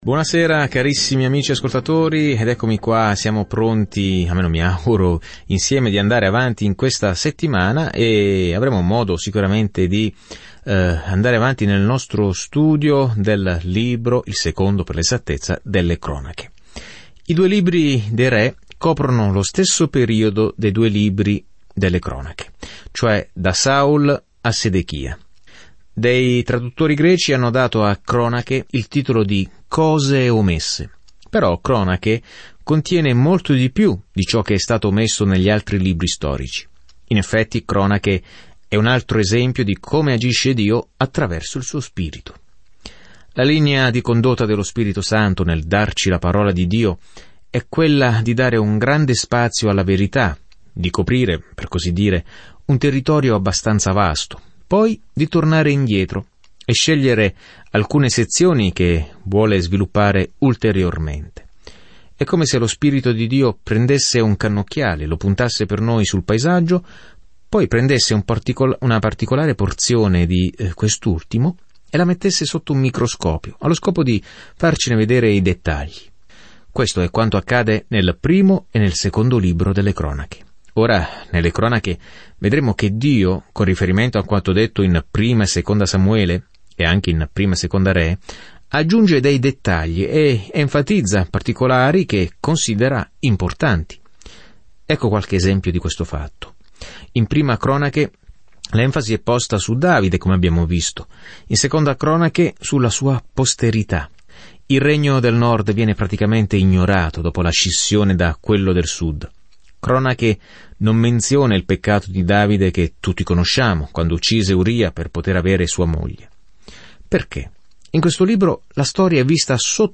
Scrittura Secondo libro delle Cronache 1 Secondo libro delle Cronache 2:1-6 Inizia questo Piano Giorno 2 Riguardo questo Piano In 2 Cronache, abbiamo una prospettiva diversa sulle storie che abbiamo sentito sui re e sui profeti del passato di Israele. Viaggia ogni giorno attraverso 2 Cronache mentre ascolti lo studio audio e leggi versetti selezionati della parola di Dio.